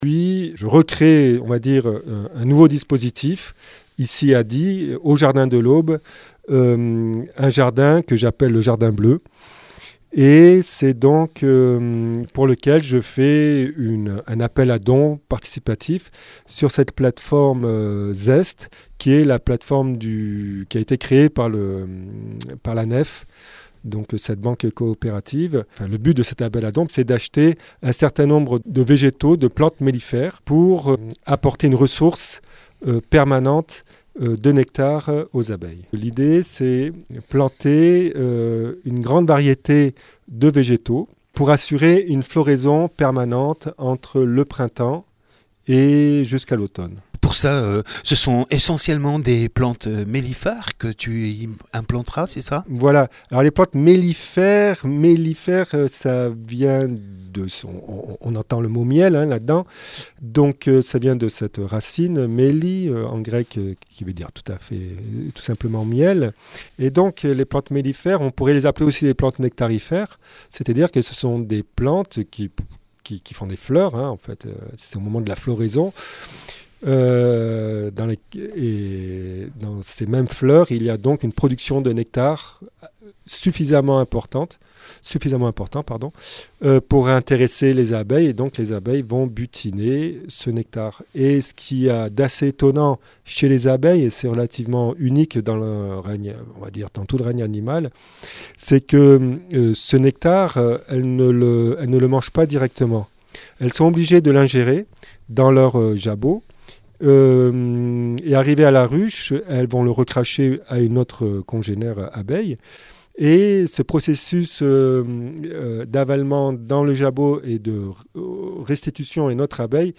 Emission - Interview
Lieu : Studio RDWA